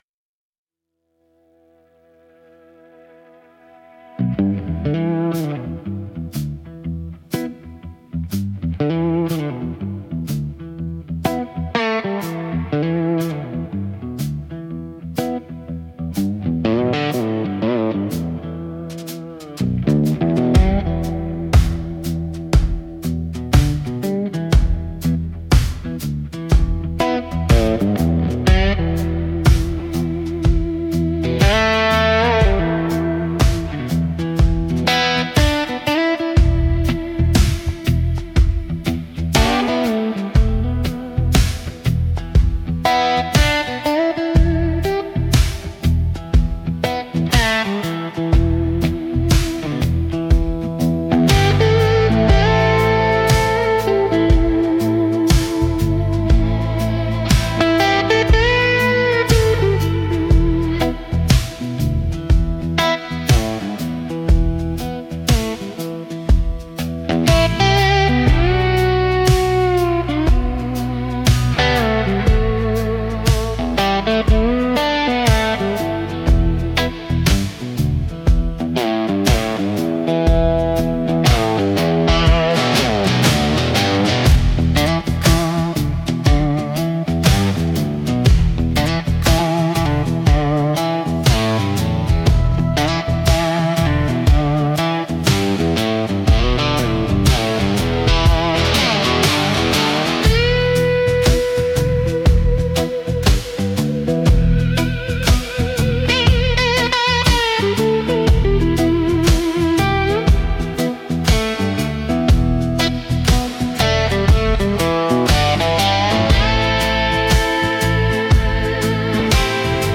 Instrumental - The Hum of Rusted Strings